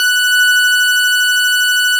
Added more instrument wavs
snes_synth_078.wav